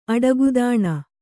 ♪ aḍagudāṇa